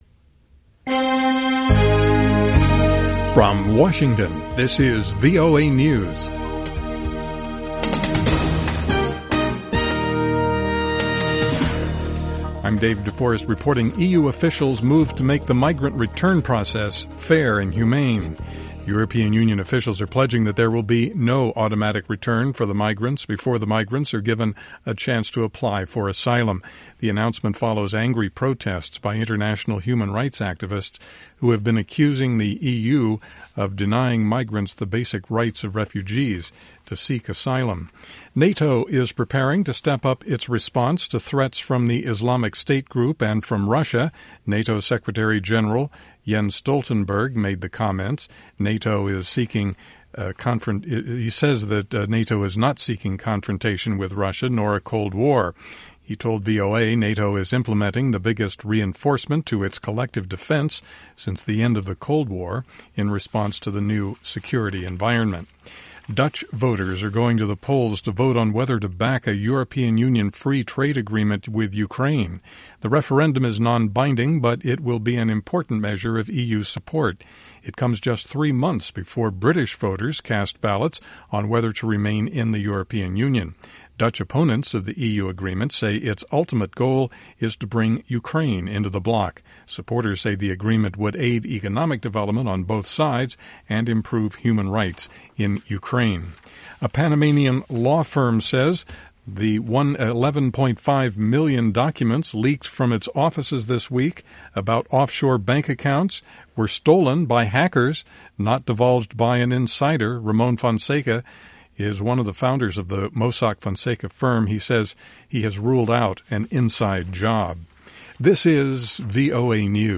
VOA English Newscast: 1900 UTC April 6, 2016